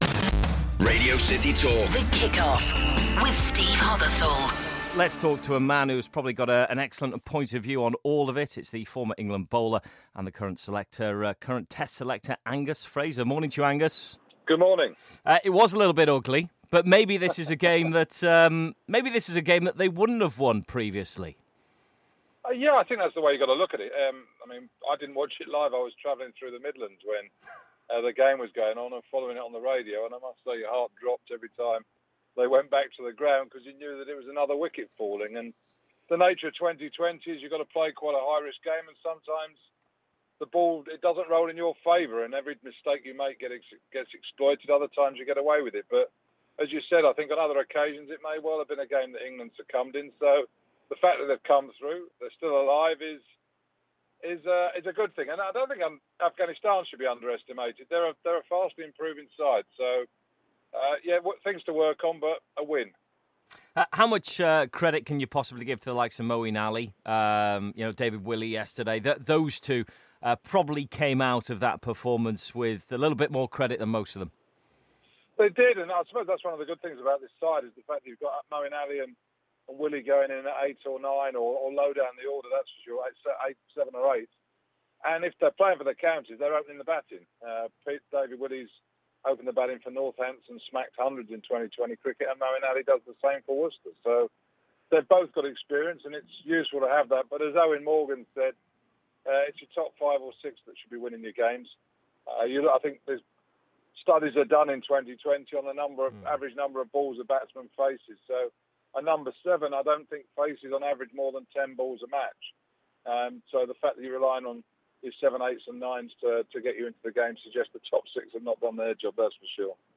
a chat about their win over Afghanistan in the World T20